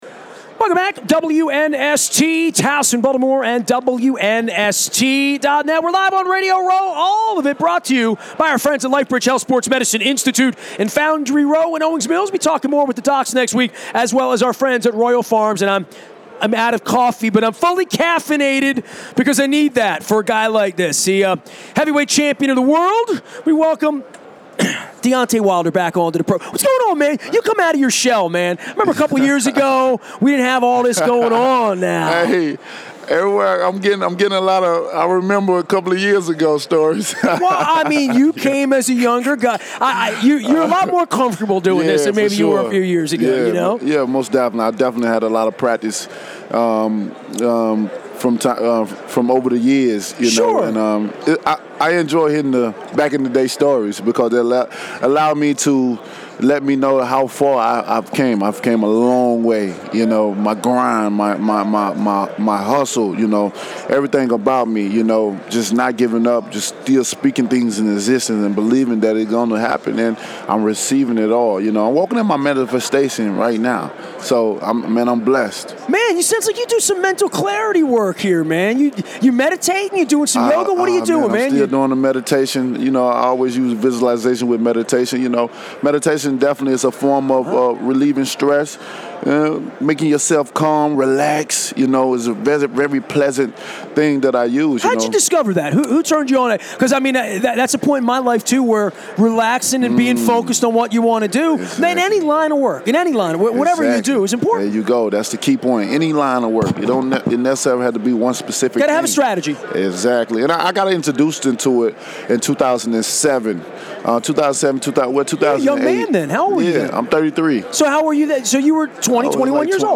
on Radio Row in Atlanta